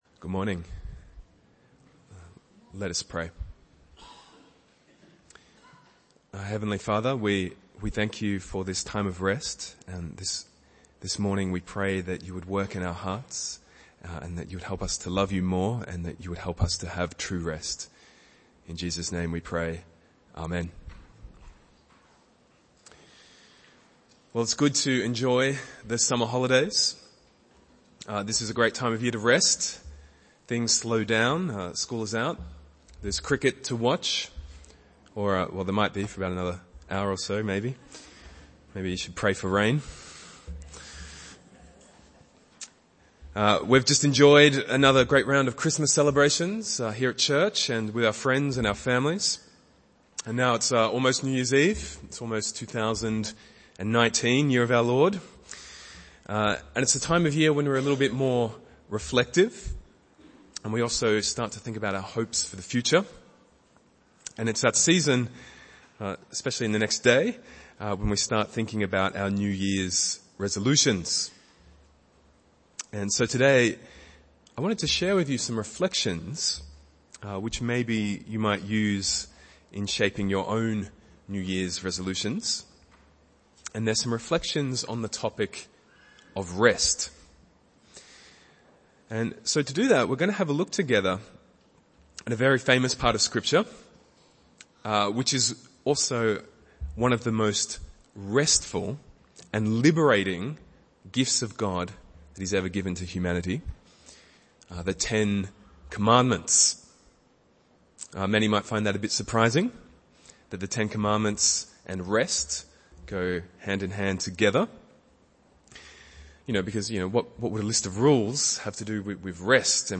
Exodus 20:1-17 Service Type: Sunday Morning Bible Text